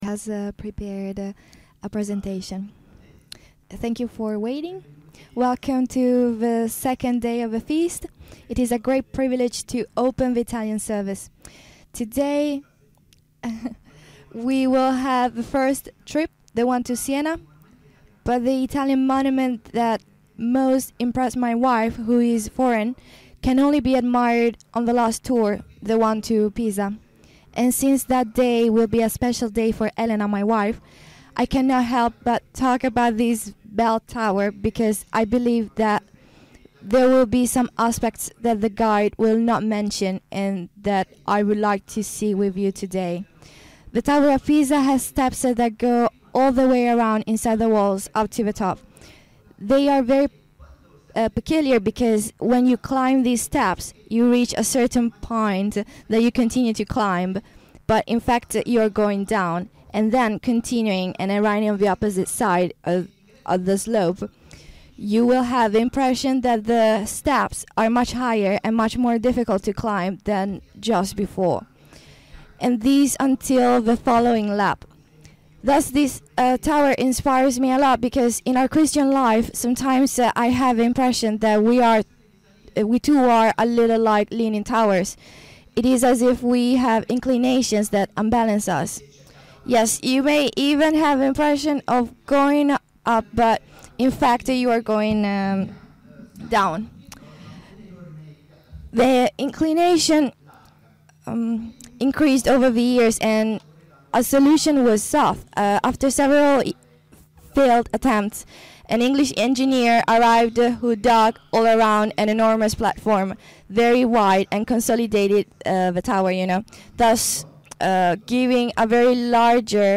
FoT 2024 Marina di Grosseto (Italy): 2nd day